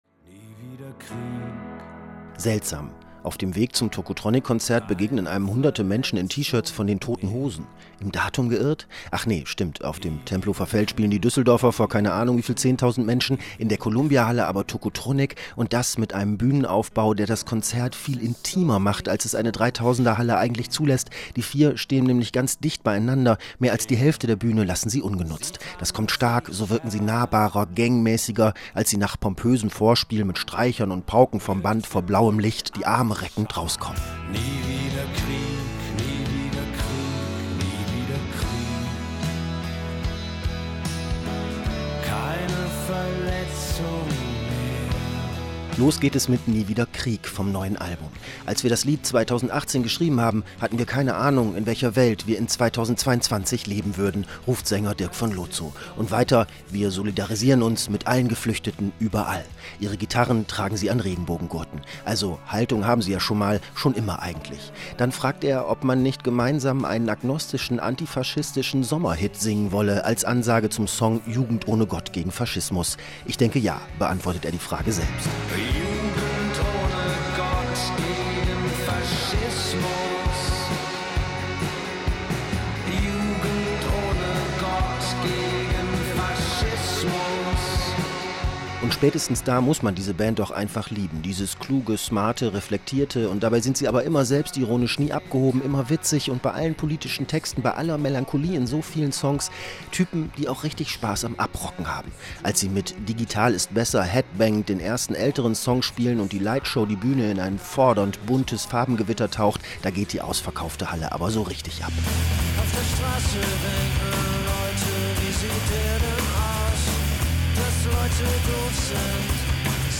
Kritik: Konzert der Band Tocotronic in der Columbiahalle Berlin | rbb24 Inforadio